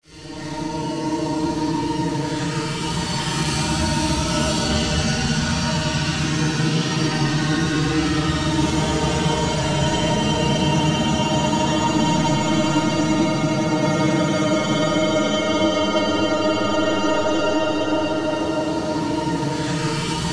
VERY GRANU.wav